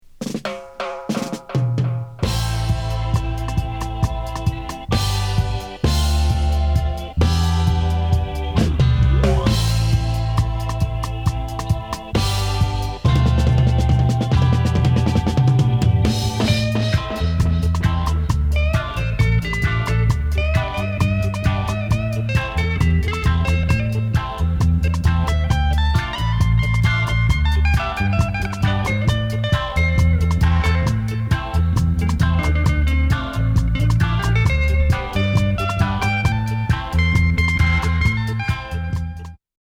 (デビッドじゃなくてね)ライクなメロディ、哀愁のトランペットが吹き荒れる！
程よいダブ処理もナイス！